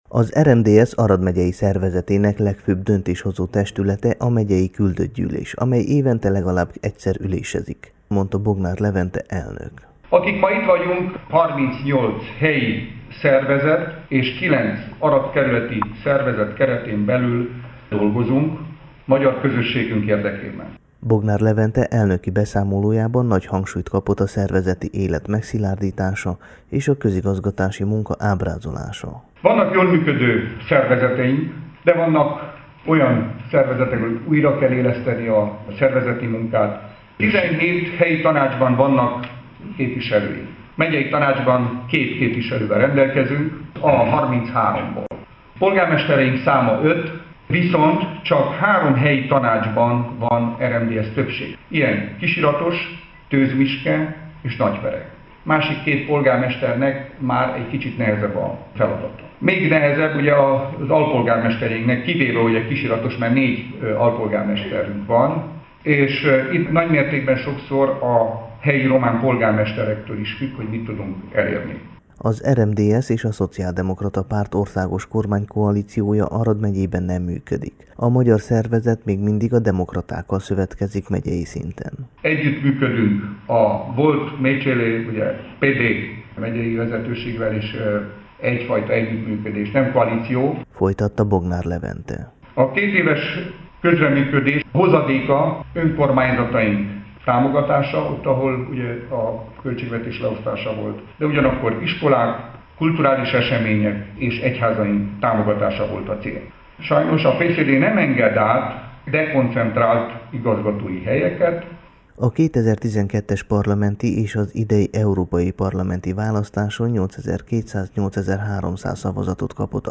a Temesvári Rádió magyar adásában